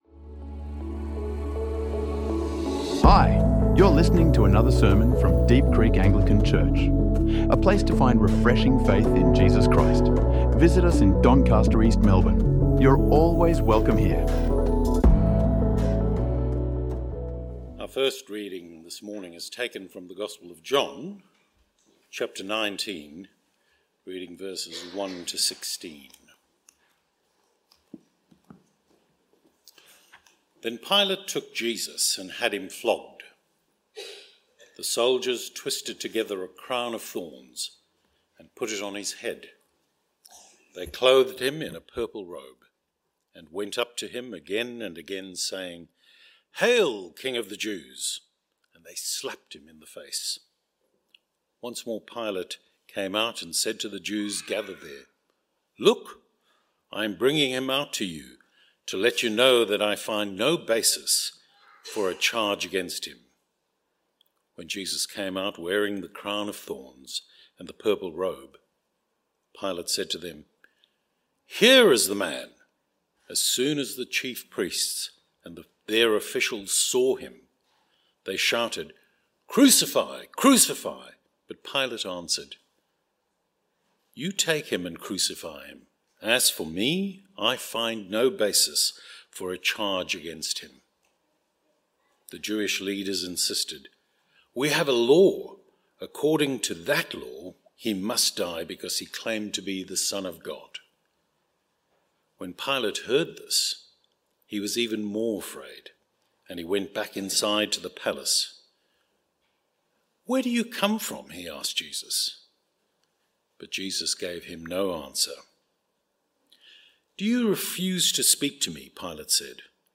Good Friday Easter Service 2026 | Sermons | Deep Creek Anglican Church